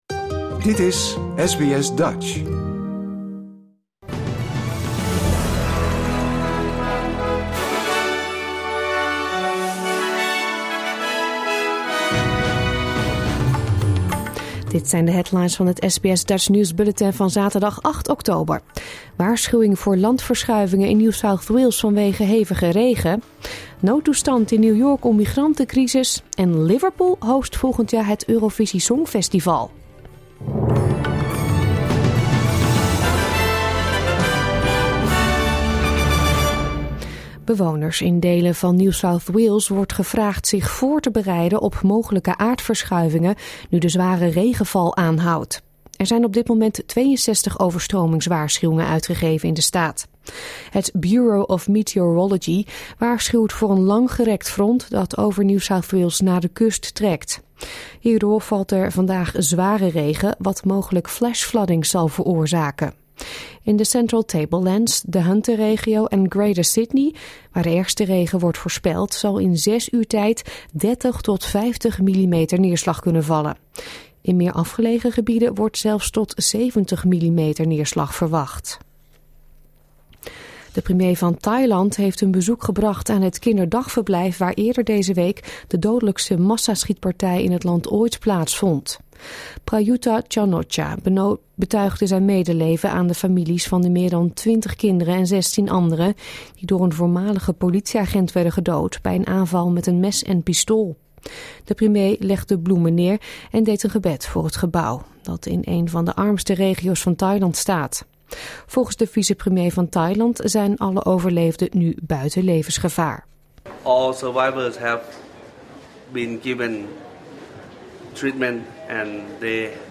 Nederlands / Australisch SBS Dutch nieuwsbulletin van zaterdag 8 oktober 2022